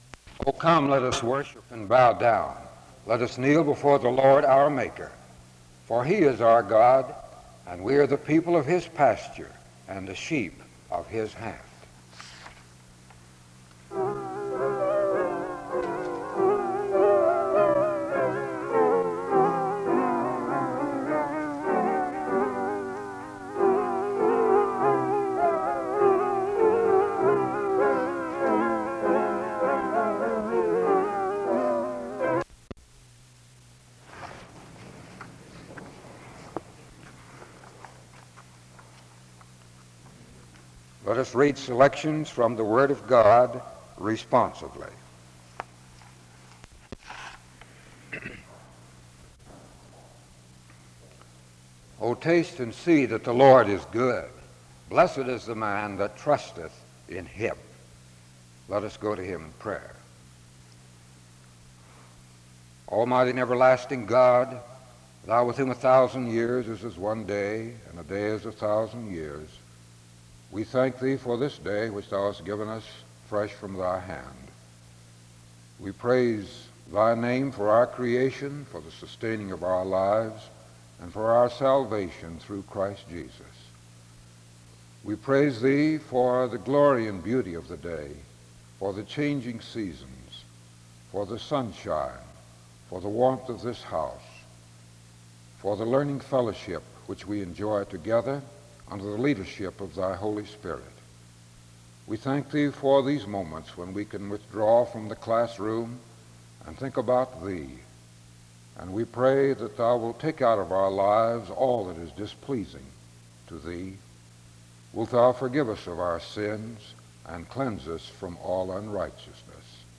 The service begins with an opening scripture reading and music from 0:00-0:33. A prayer is offered from 0:40-4:57. An introduction to the speaker is given from 5:00-6:06.